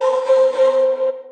loop (vox2).wav